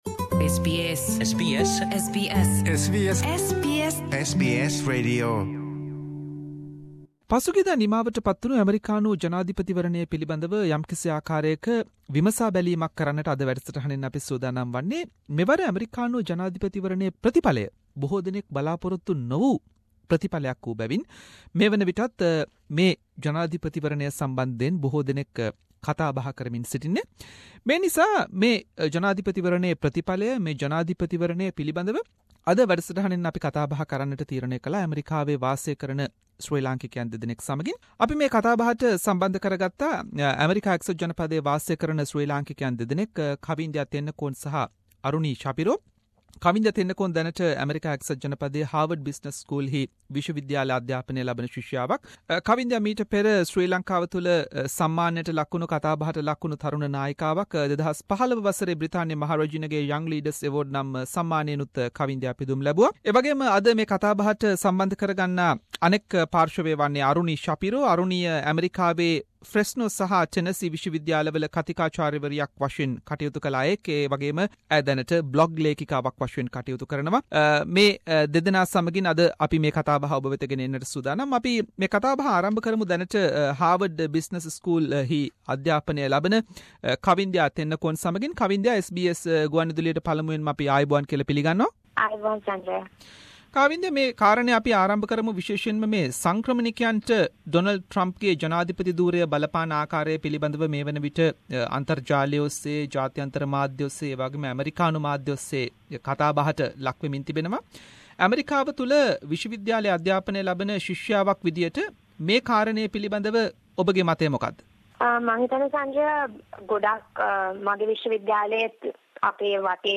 Analysis of this US elections with two Sri Lankan academics live in USA.